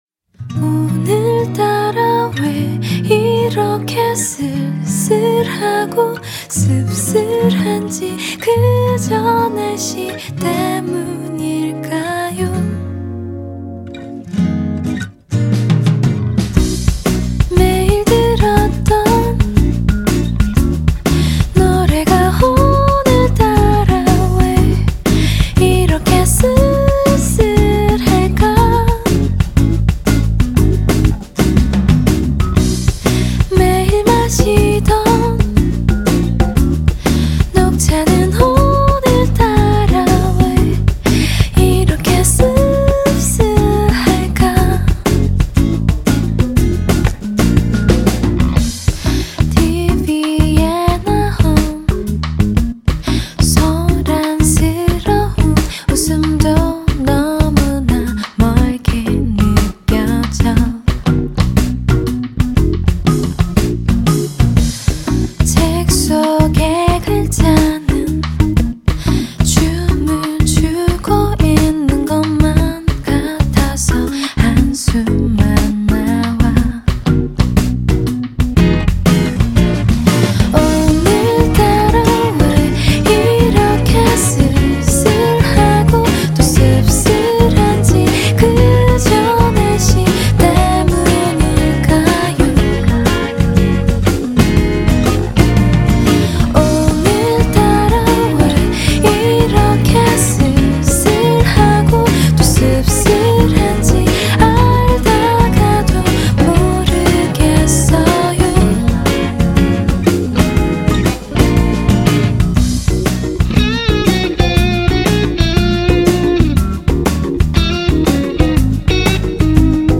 발음이 정확해서 깜짝 놀랐습니다.